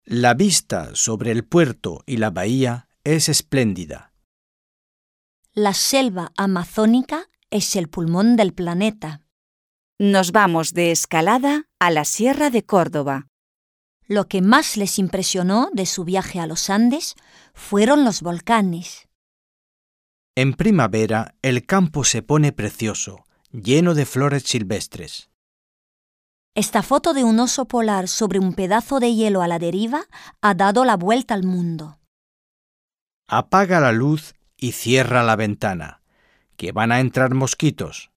Un peu de conversation - Nature et animaux